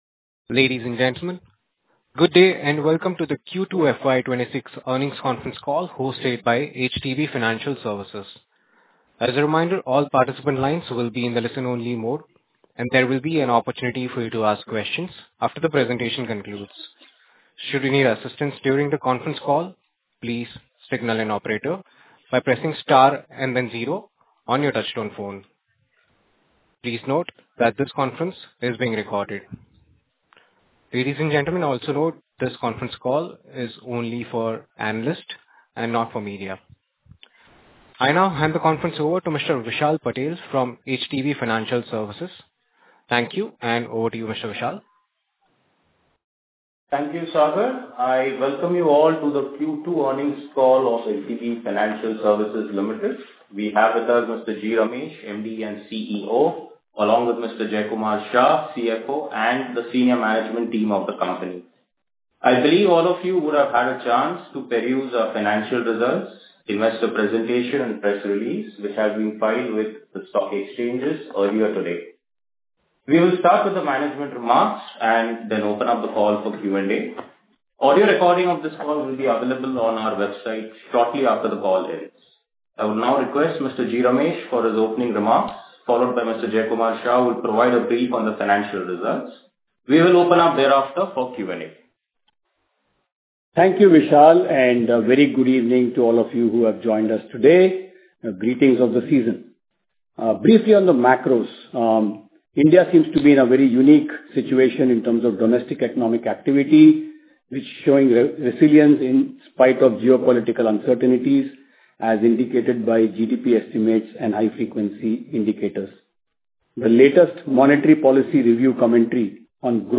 HDBFS-Q2FY26-Concall-Audio.mp3